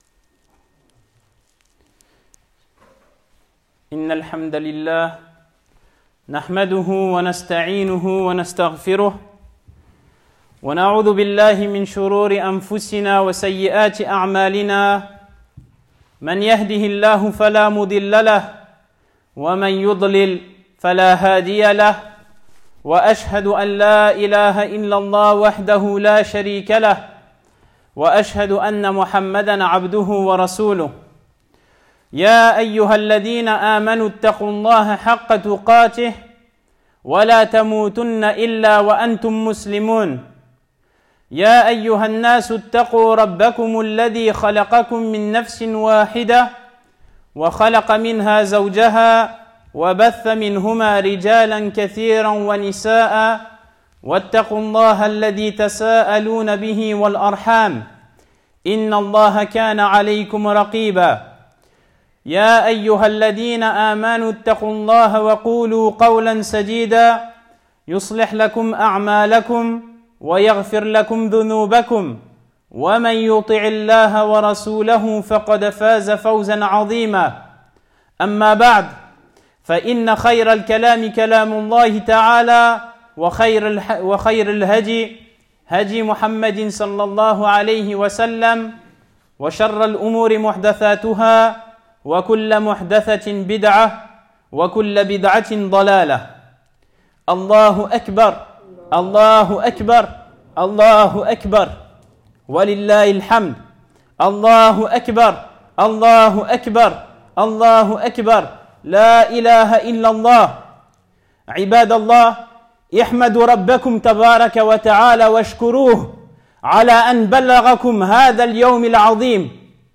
Dans ce prêche intitulé « ۶Îd Al-Adhâ 1441H – 2020G » argumenté par des versets du livre d’Allah (ﷻ) et des hadith de la Sunnah du Prophète Muhammed (ﷺ), selon la compréhension…